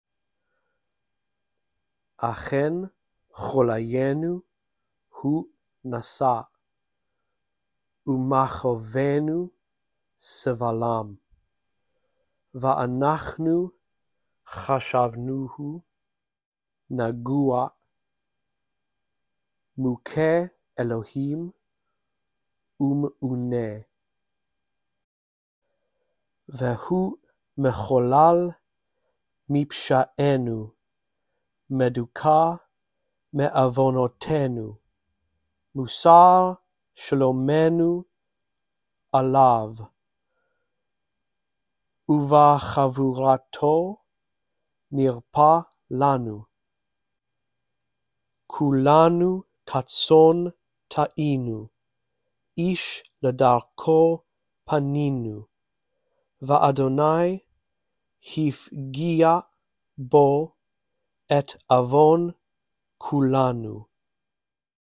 1. Listen to me read all of Isaiah 53:4-6, following along in the text below.